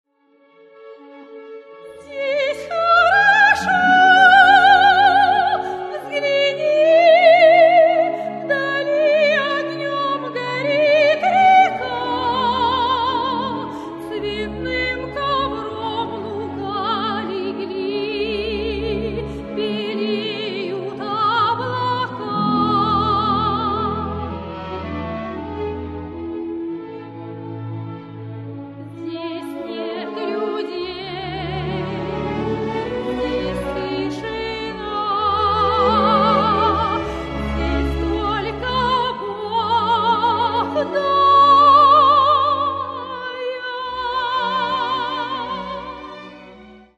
Каталог -> Классическая -> Опера и вокал